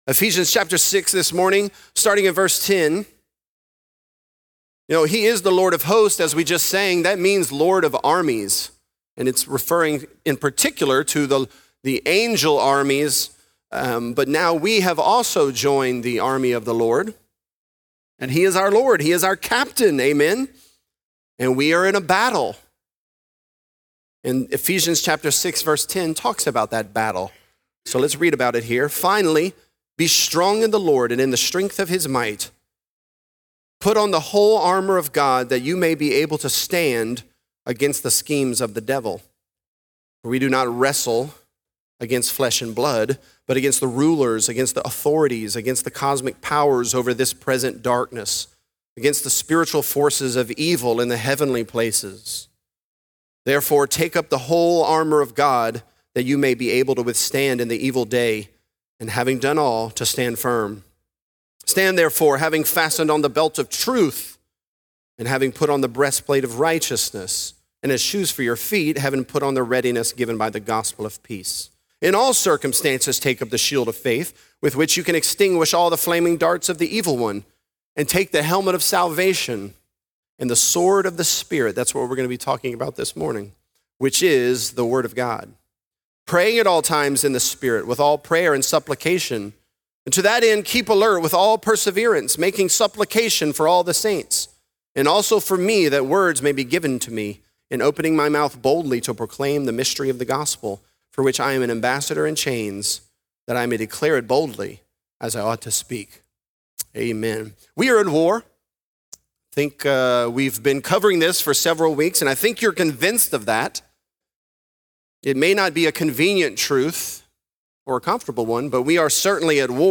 The Armor Of God: Sword Of The Spirit | Lafayette - Sermon (Ephesians 6)